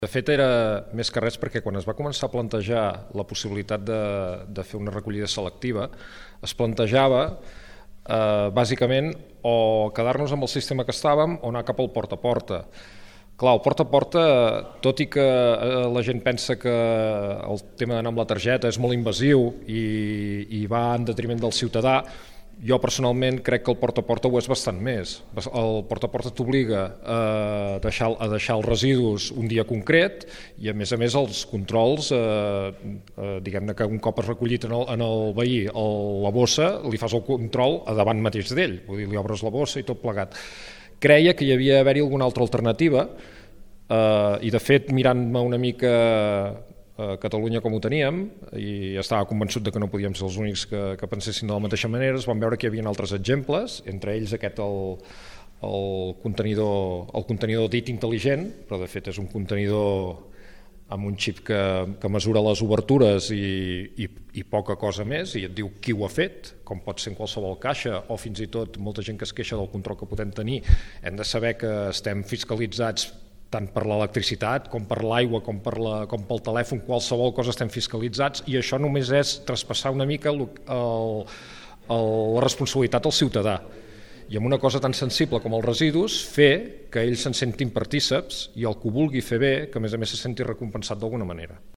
ÀUDIO: L’alcalde de Rocafort de Queralt justifica la iniciativa